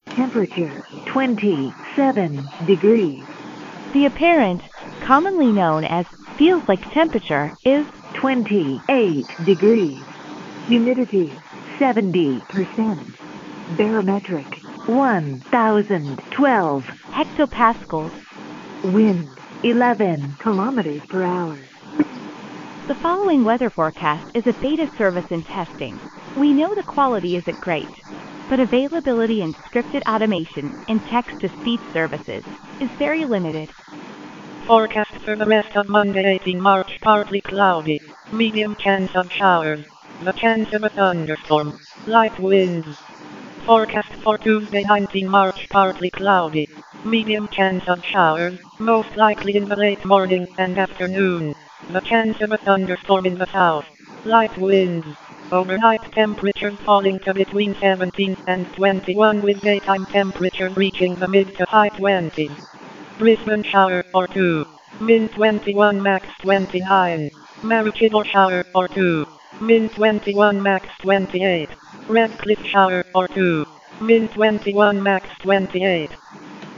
If you're not in Australia, you can probably skip this next Forecast section, you might be able to adapt it to your region but I'll be of no help, even if you're in Aus, at present you may wish to omit this as well, text to speech services that can be used in a scripted fully automated fashion are very limited, and the good ones are not generally within most peoples acceptable budget, so we're using linux's included espeak, yes - the quality is very robotic, but it does get the information out and I guess is better than nothing, there are others like vsox and festival, but they too are robotic sounding, and much of a muchness.
Brief example of Weather Audio, MP3 @128 kb/s, 1m16s